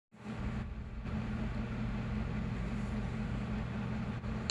Extreme setting at idle.
At the extreme setting, the H100i starts sounding fairly loud even at idle but it never reaches a point where the sound can’t be drowned out by some headphones.
extreme-idle.m4a